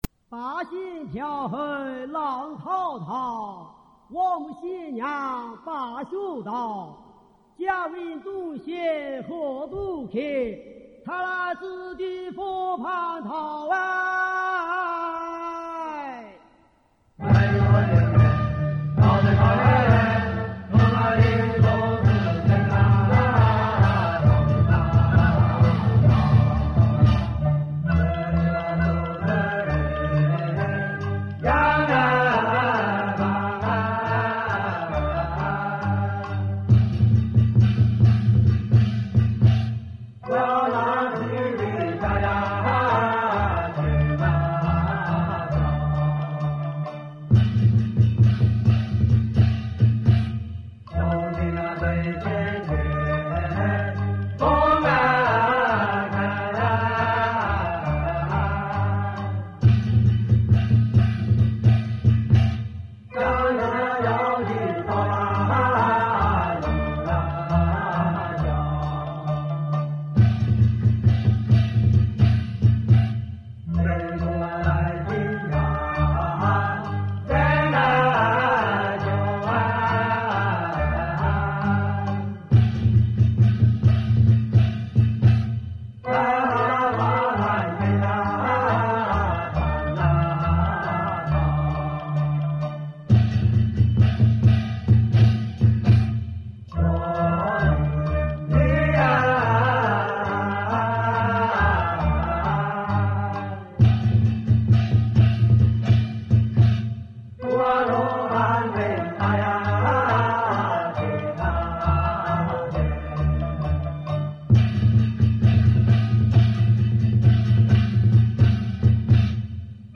本首曲子为龙虎山天师府演奏，为龙虎韵。
(江西龙虎山天师府道乐)